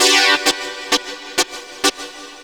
SYNTHLOOP1-L.wav